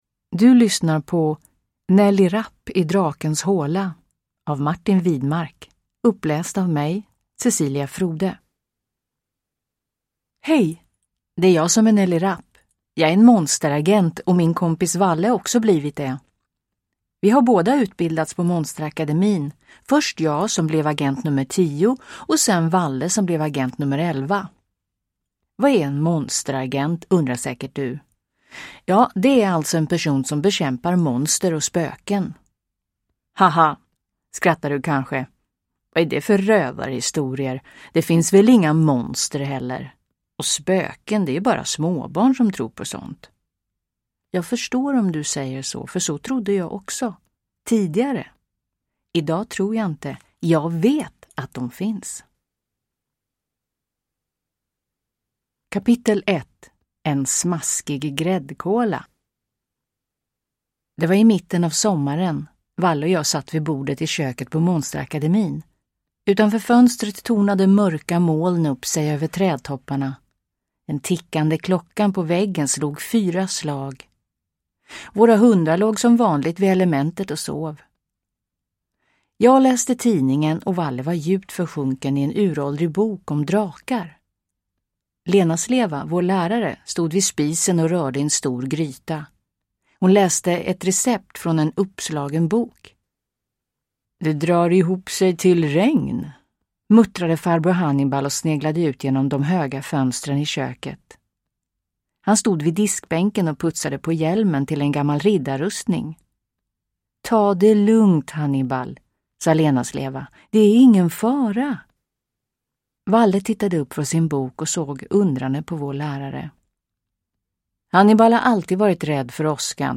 Nelly Rapp i drakens håla – Ljudbok